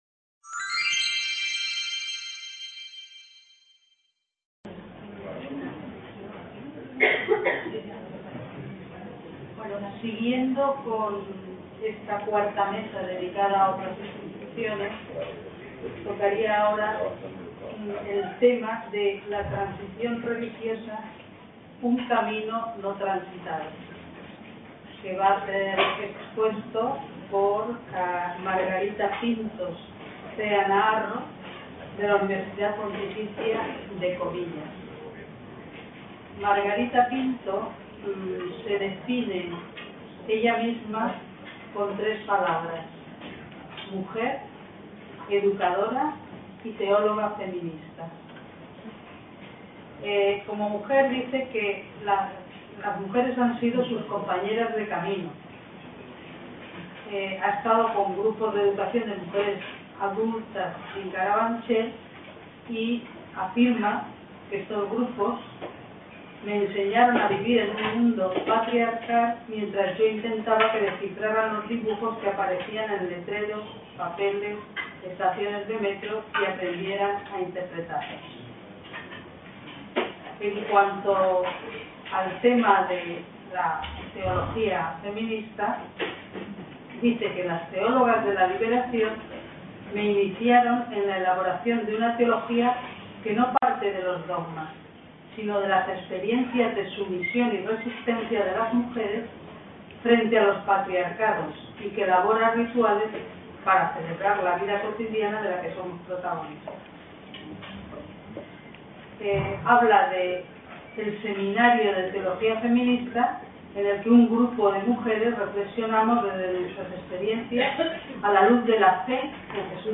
C.A. Almeria - VI Congreso Internacional Historia de la Transición en España, Las instituciones.